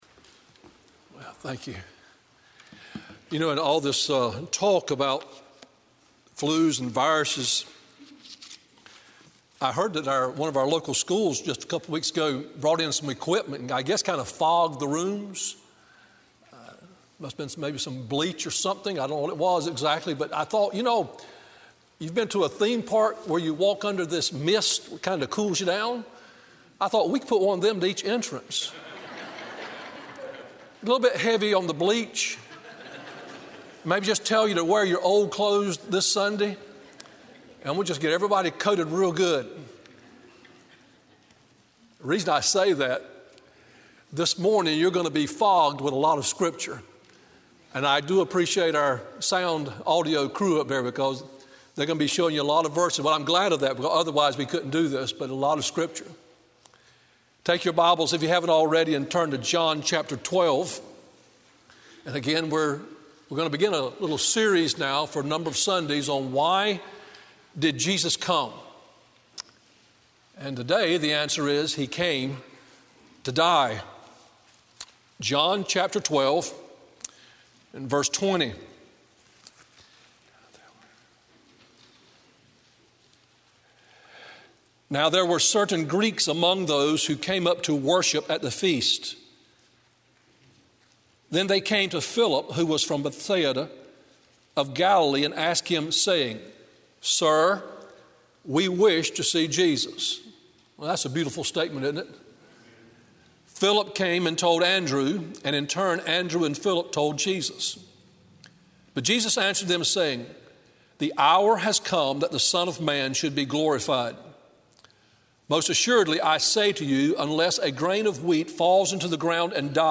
Sermon Audios/Videos - Tar Landing Baptist Church
March 8 2020 Why did Jesus Come? He Came to Die. Morning Worship John 12:20-33 Matthew 16:16-17 NKJV ; John 1:40-41 NKJV 1.